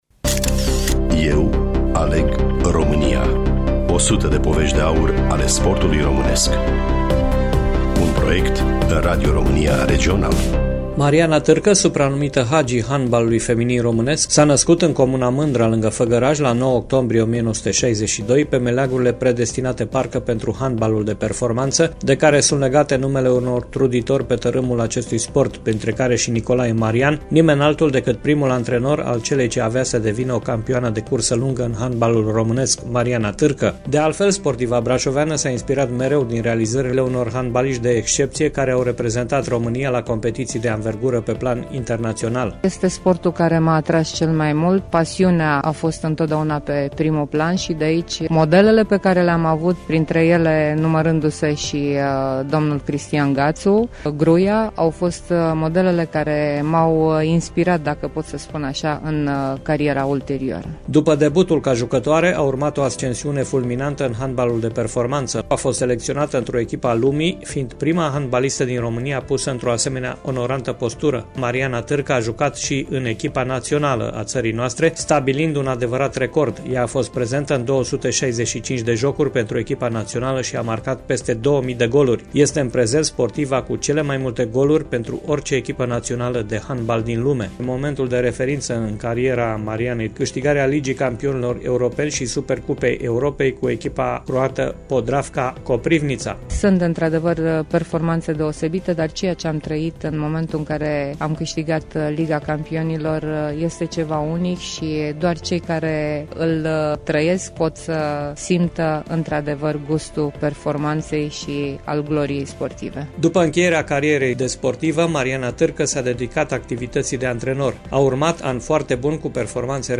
Studioul: Radio România Tg.-Mureş